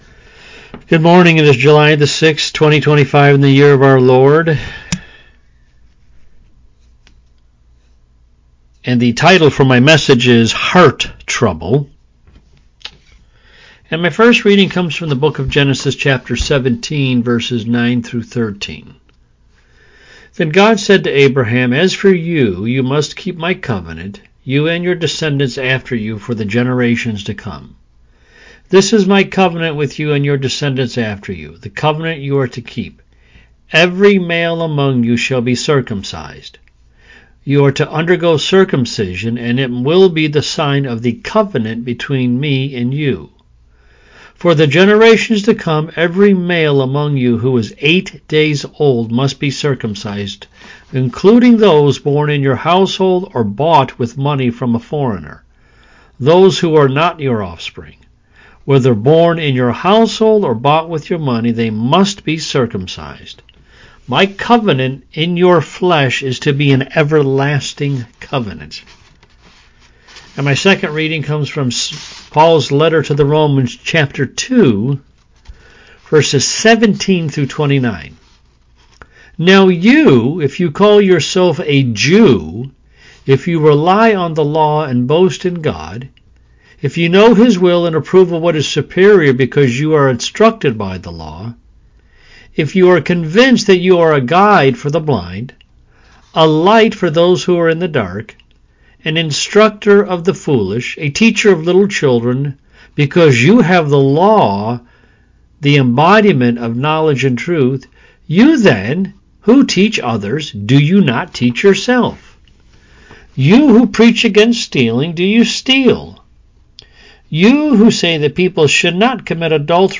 This is the first in our sermon series - From Almost to Altogether - Sermons on Christian Discipleship by John Wesley.